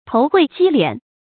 頭會箕斂 注音： ㄊㄡˊ ㄎㄨㄞˋ ㄐㄧ ㄌㄧㄢˇ 讀音讀法： 意思解釋： 頭會：按人頭征稅；箕斂：用畚箕裝取所征的谷物。